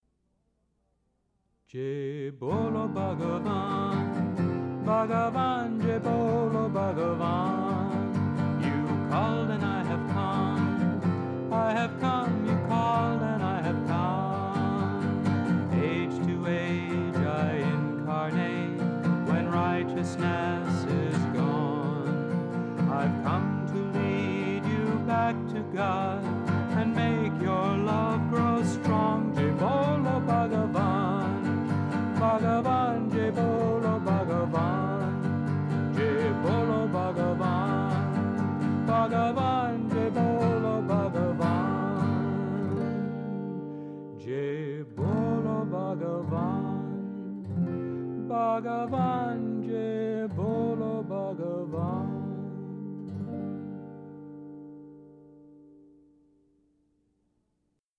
1. Devotional Songs
Minor (Natabhairavi)
8 Beat / Keherwa / Adi